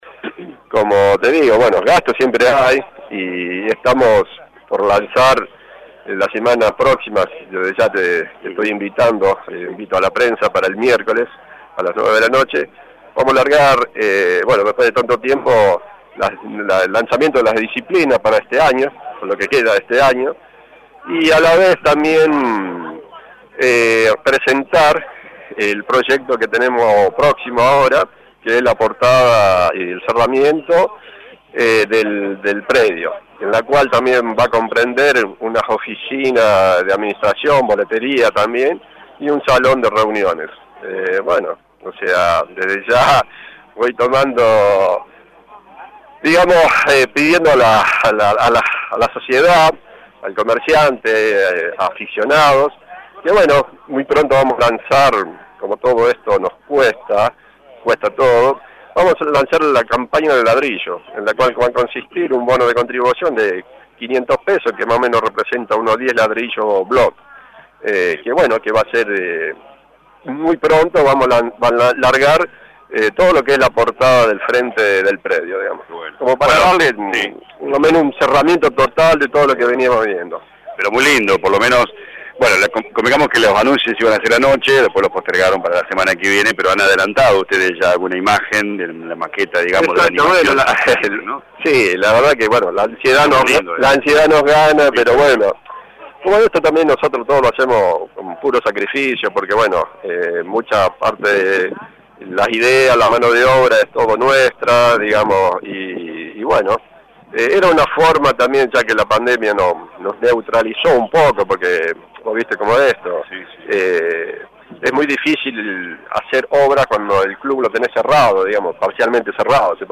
En dialogo con LA RADIO 102.9